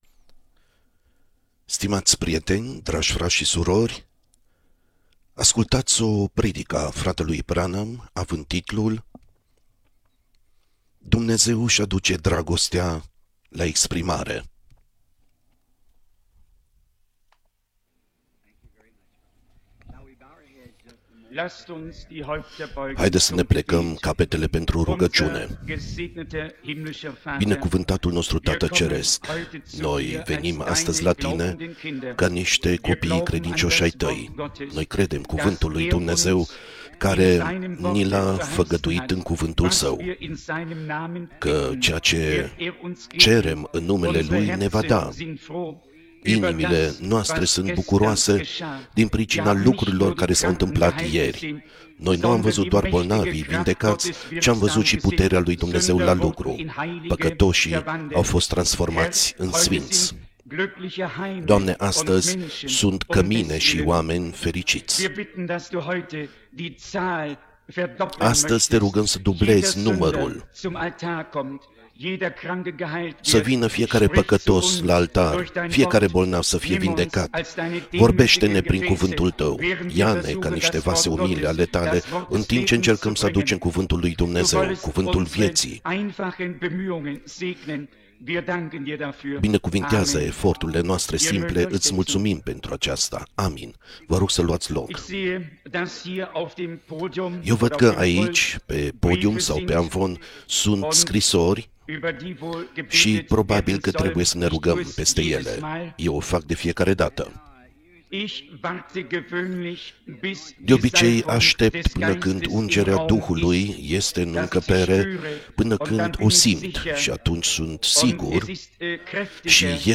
Predica
6 august 1957 Edmonton, Canada